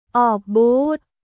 ออกบูธ　 オークブース